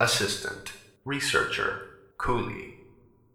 scientist12.ogg